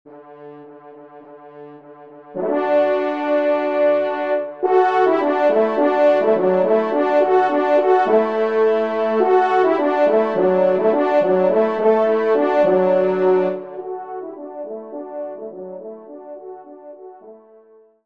Genre :  Divertissement pour Trompe ou Cor et Piano
2e Trompe